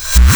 REVERSBRK1-L.wav